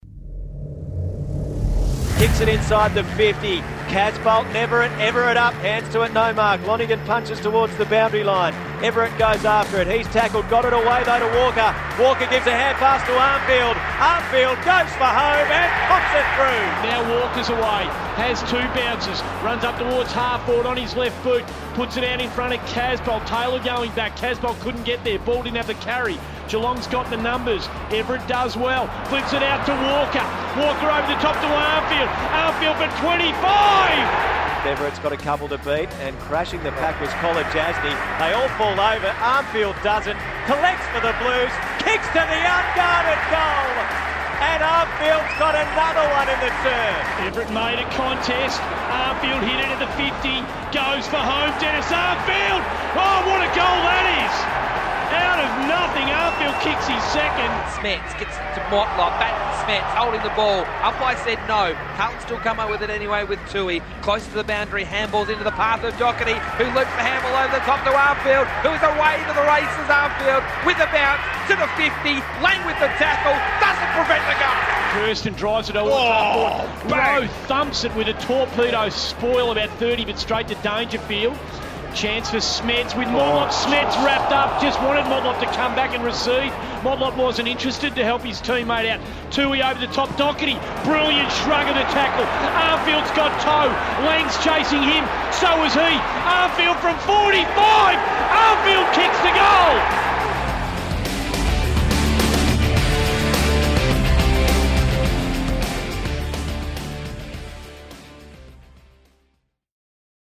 Listen to ABC Grandstand and SEN 1116's call of Dennis Armfield's thrilling three goals in Carlton's win over Geelong.